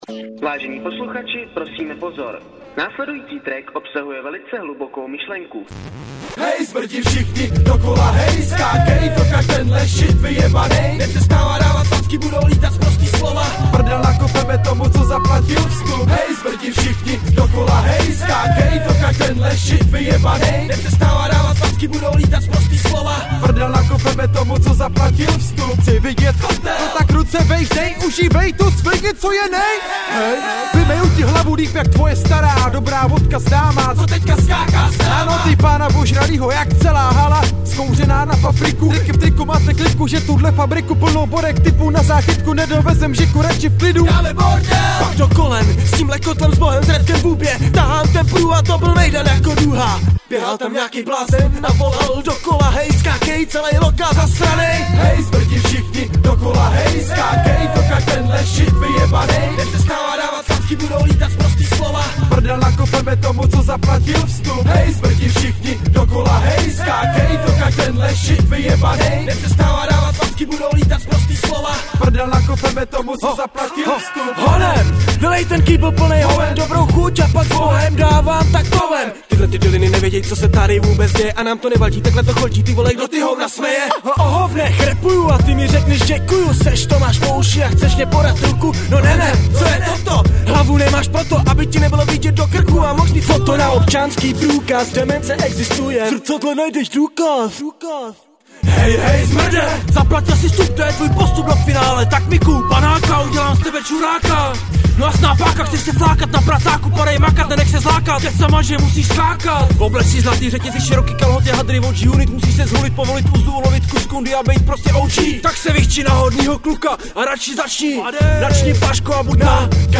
8 Styl: Hip-Hop Rok